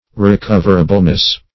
recoverableness - definition of recoverableness - synonyms, pronunciation, spelling from Free Dictionary
[1913 Webster] -- Re*cov"er*a*ble*ness, n.